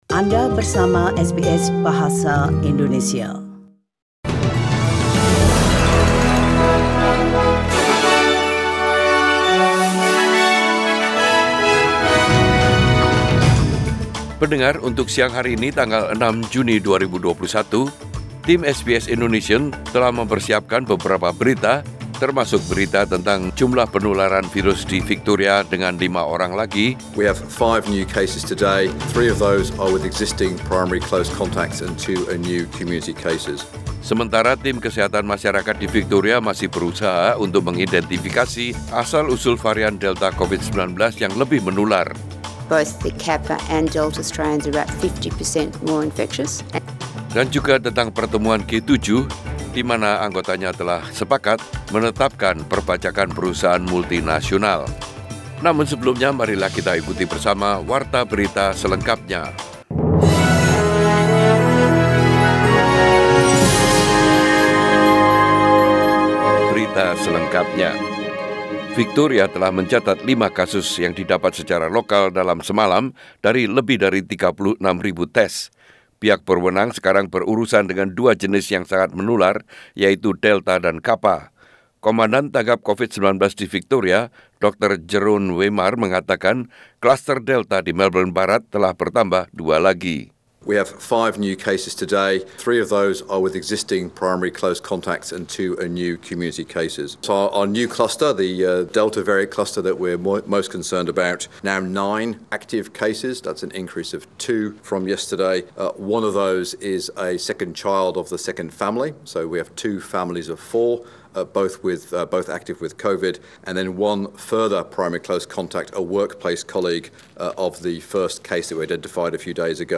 SBS Radio News in Bahasa Indonesia - 6 June 2021
Warta Berita Radio SBS Program Bahasa Indonesia.